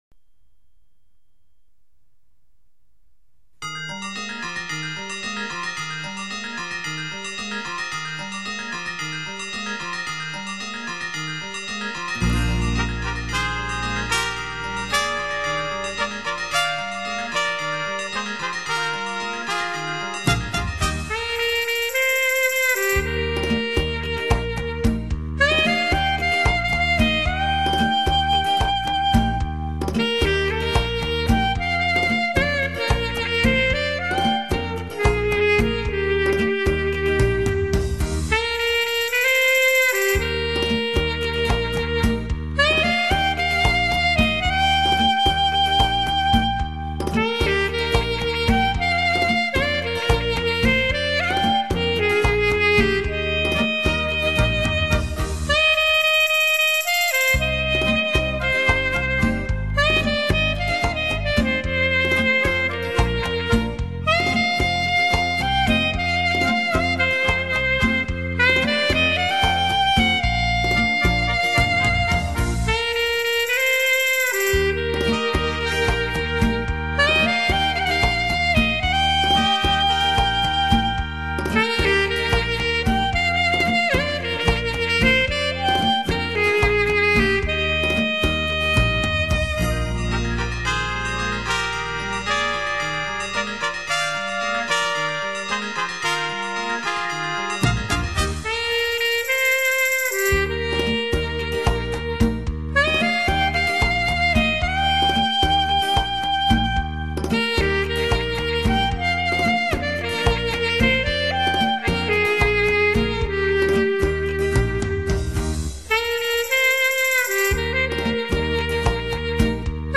悠悠的萨克斯，吹响的是那淡淡的感伤，浓浓的思绪；
沏上一杯浓香的咖啡，耳边飘起怀旧与感性的萨克斯
没想到，悠悠的萨克斯也可有如此豪气，灵秀。